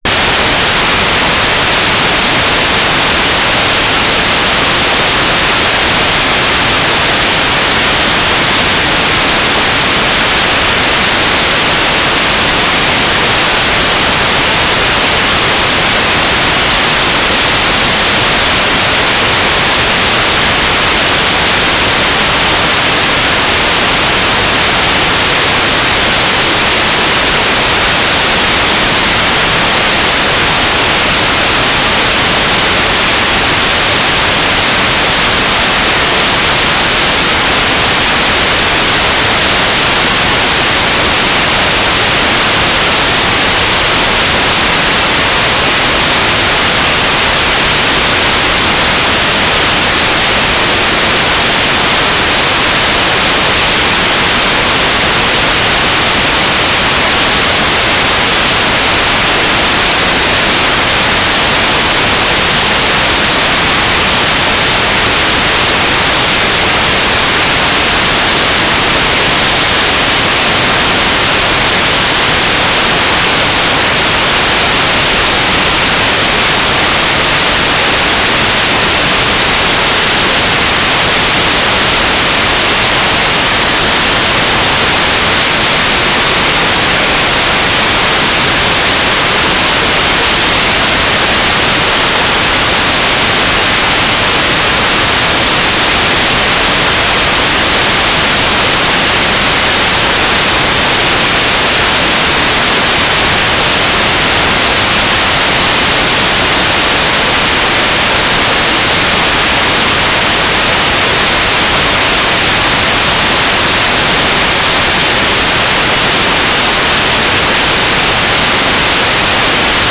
"transmitter_description": "Mode U - GFSK9k6 - Telemetry",